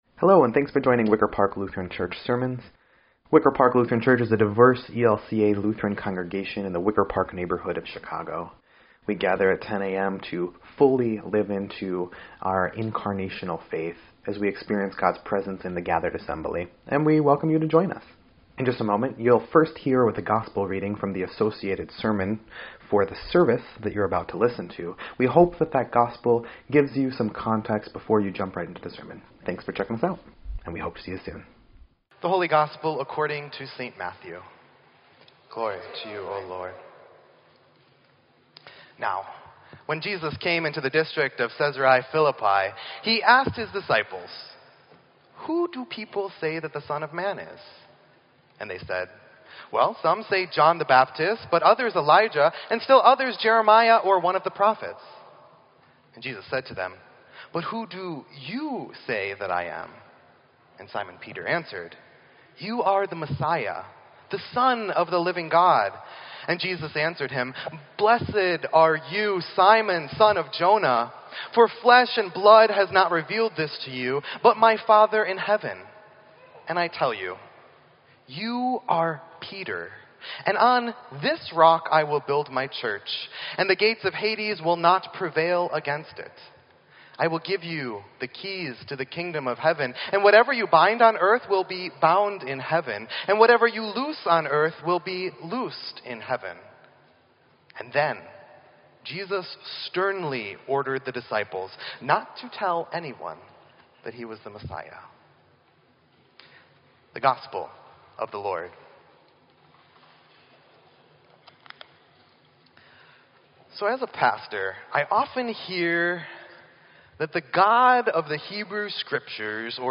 Sermon_8_27_17_EDIT.mp3